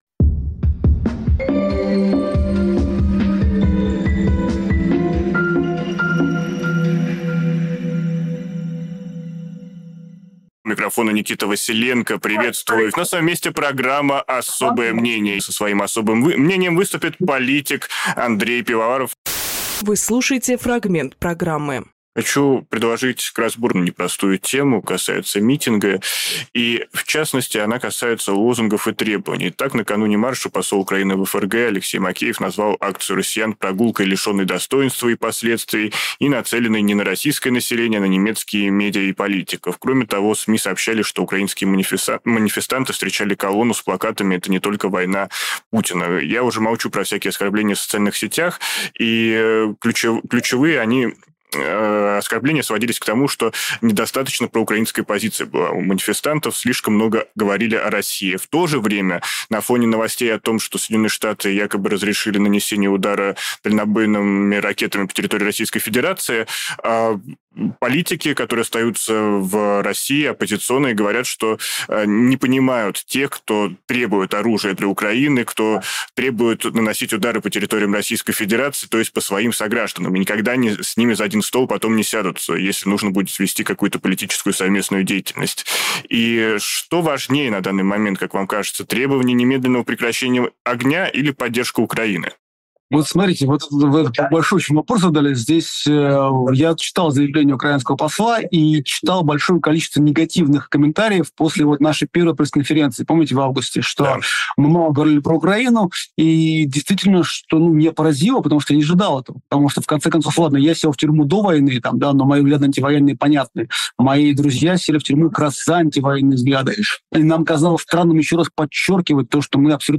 Фрагмент эфира от 18.11